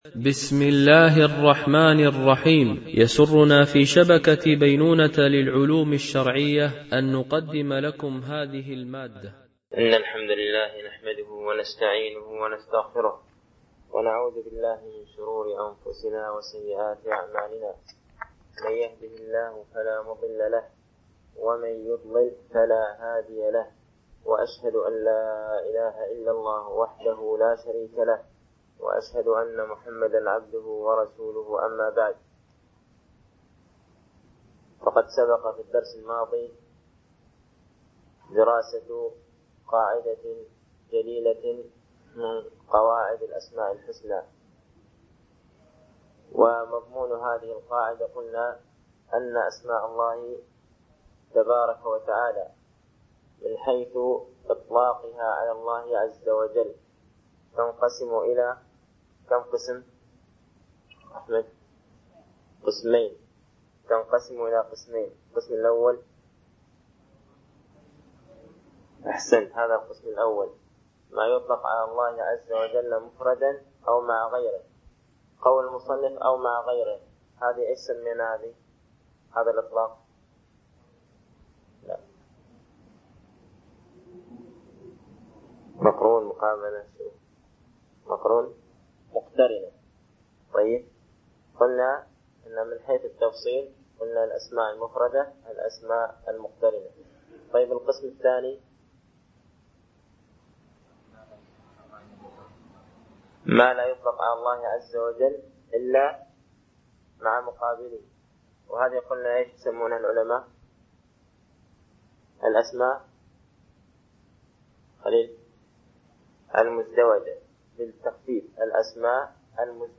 الألبوم: شبكة بينونة للعلوم الشرعية التتبع: 26 المدة: 24:49 دقائق (5.72 م.بايت) التنسيق: MP3 Mono 22kHz 32Kbps (CBR)